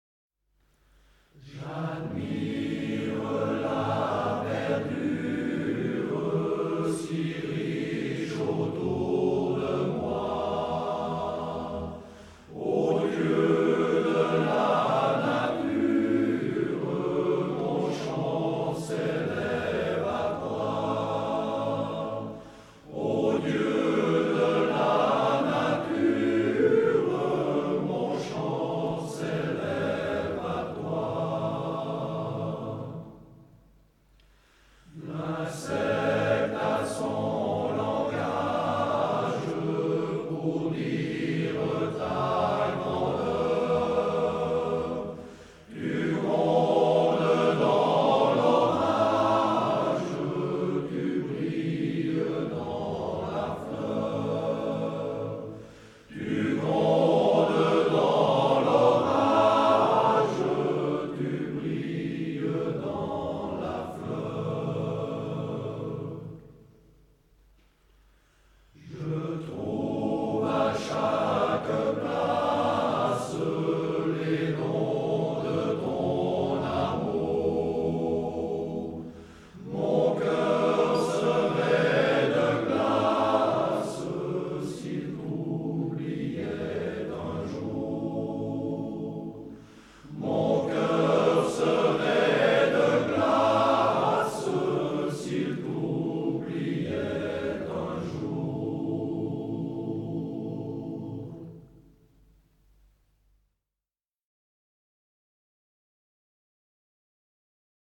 Version originale
par La Concorde · Eclépens
H10045-Live.mp3